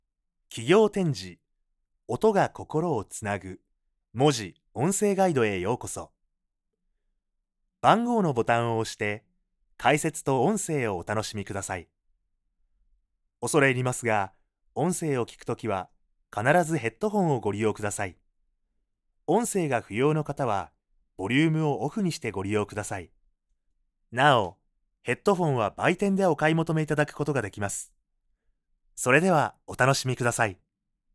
I can adapt my voice to your needs, from a calm tone to a bouncy, energetic voice.
Can speak Kansai dialect.
Audio Guide for Museums